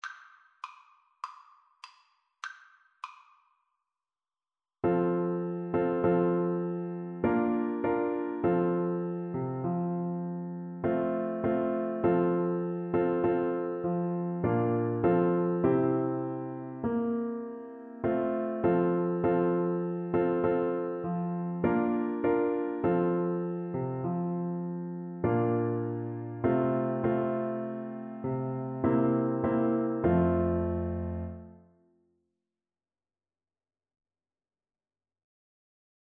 Bassoon
4/4 (View more 4/4 Music)
F major (Sounding Pitch) (View more F major Music for Bassoon )
Cantabile =c.100
Traditional (View more Traditional Bassoon Music)